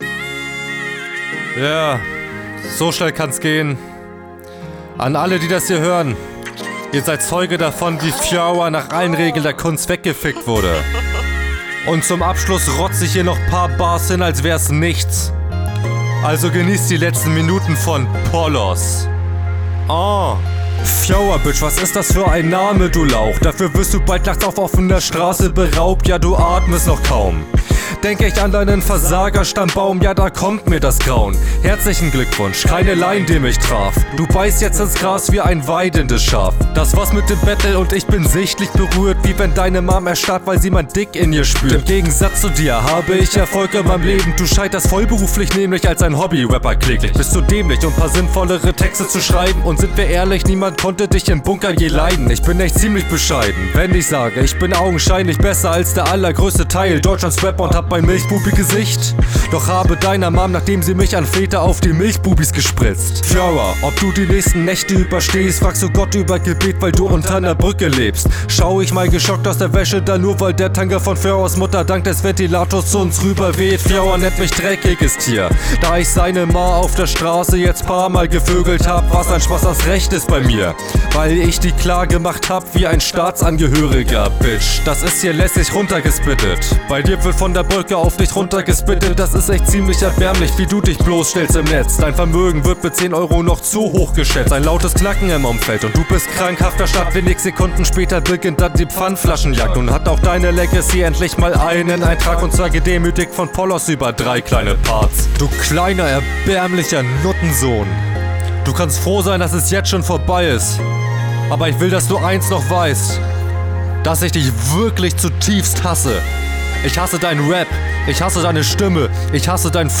Der Beat ist aber n bissl einschläfernd tbh hehe Die Namen Zeilen sind okay, aber …